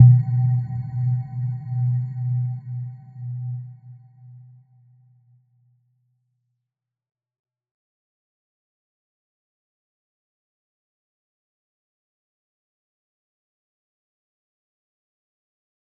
Little-Pluck-B2-f.wav